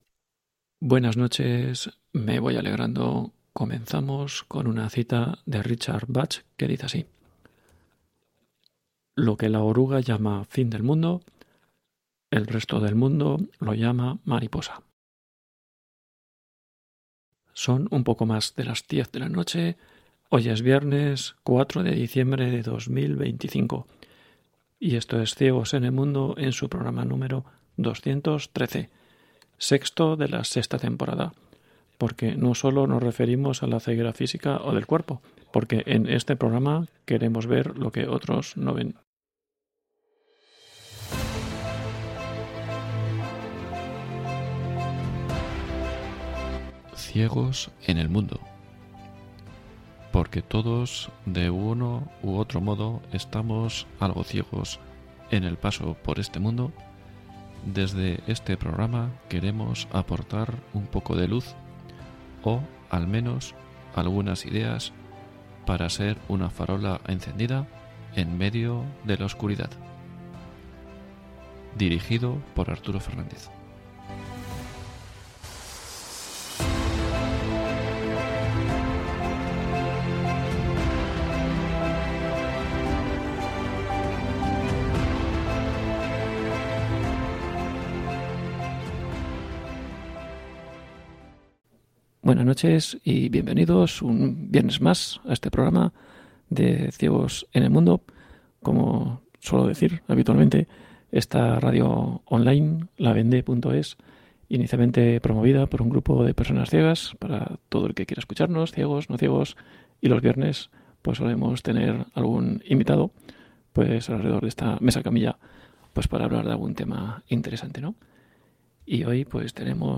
📻 El programa de radio "Ciegos en el mundo" entrevista a ASOCIDE CLM.